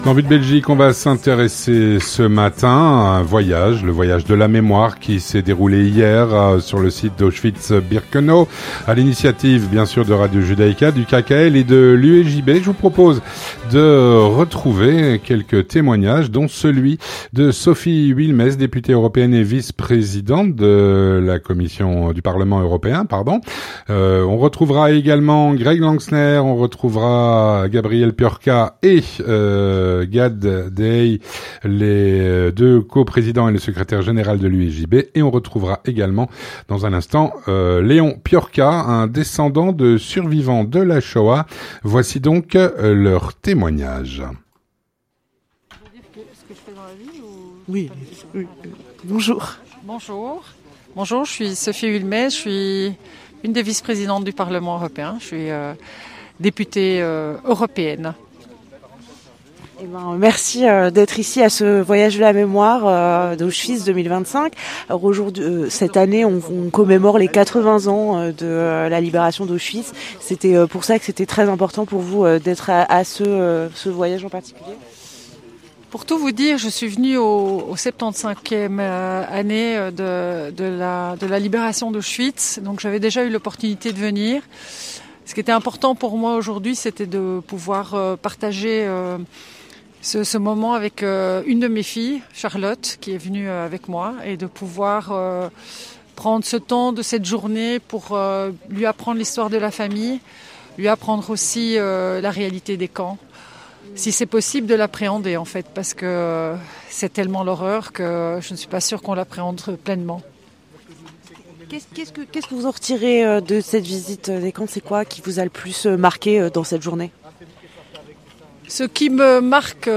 Organisé par Radio Judaïca, le KKL et l'UEJB, un "Voyage de la Mémoire" a eu lieu sur les sites d'Auschwitz-Birkenau, ce dimanche 23 mars.
Sophie Wilmès, députée européenne et Vice-Présidente du Parlement Européen.